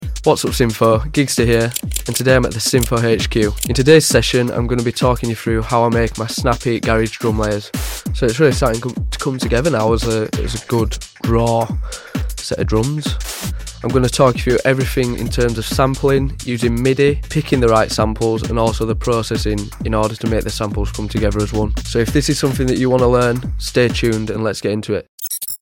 If you love that skippy & fast sound that wobbles the clubs.